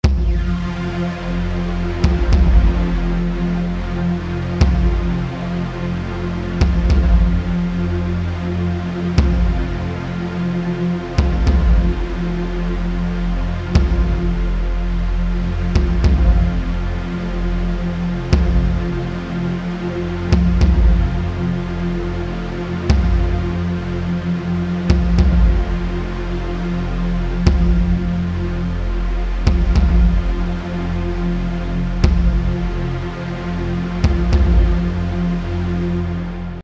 Eine andere beliebte Spielart ist die Rhythmisierung eines Flächensounds, den man durch einen Beat/Percussion-Track komprimiert.
Zunächst die Bassdrum und der Flächensound (aus Omnisphere 2) ohne den Drawmer 1973 …